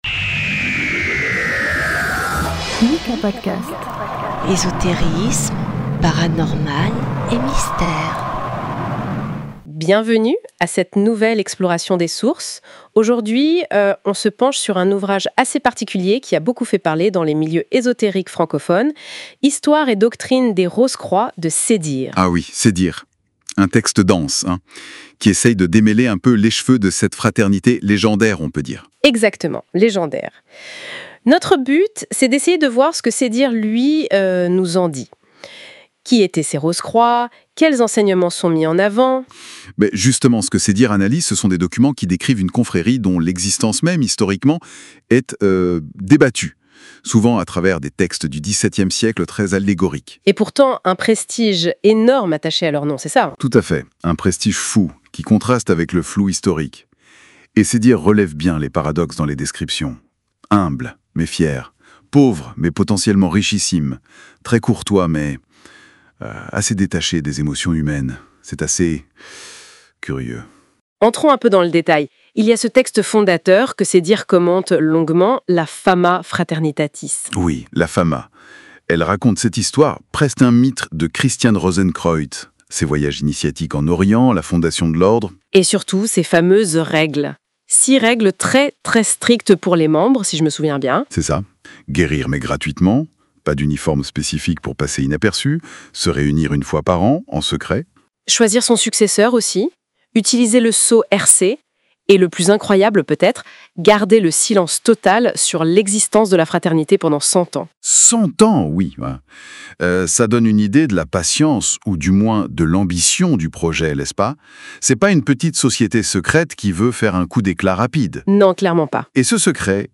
🎙 Présentation du livre – Histoire et Doctrine des Rose-Croix.